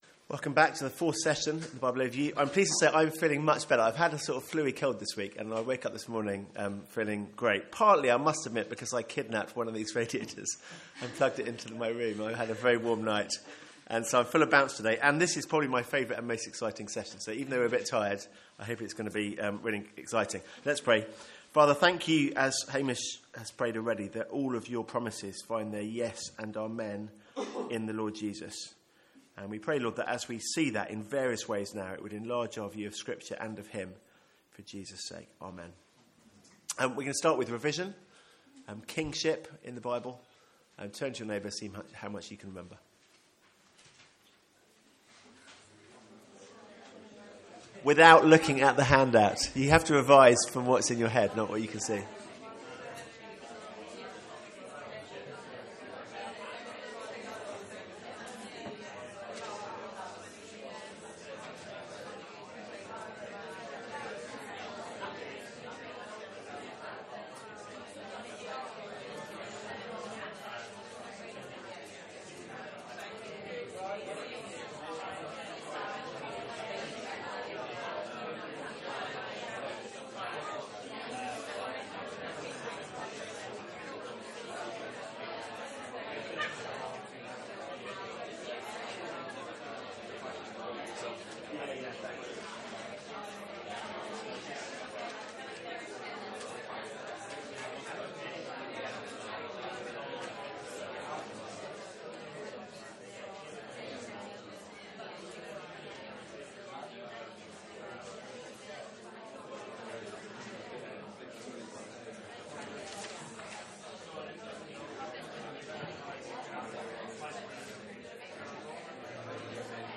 From our student Mid-Year Conference.